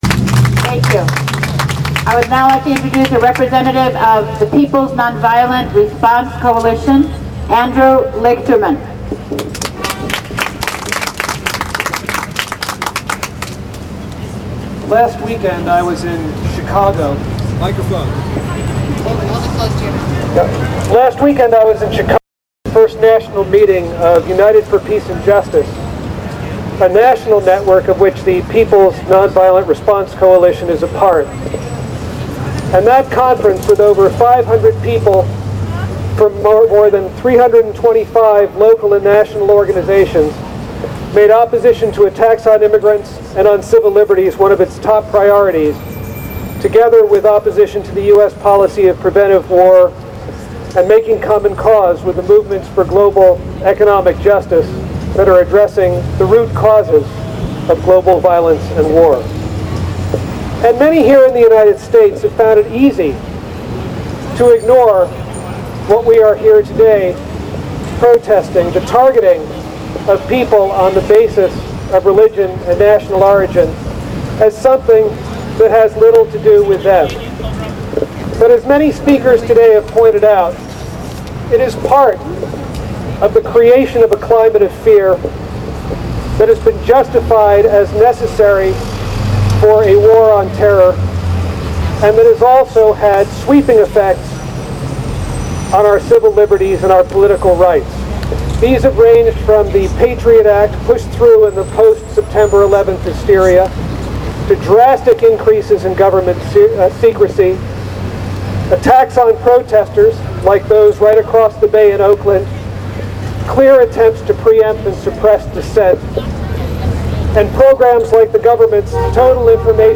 This footage is from the protest in front of the INS building that took place from noon to 1pm at 444 Washington Street in San Francisco on June 13, 2003.